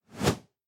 Card_Zoom_In.mp3